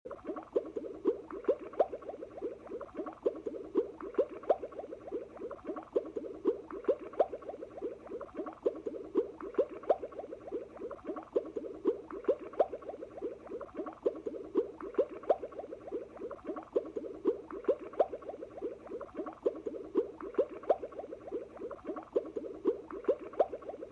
Bubbles Sound Meditation, Sleep| Sound Effects Free Download
Bubbles Sound - Meditation, Sleep| Nature Sounds| Relaxing Sounds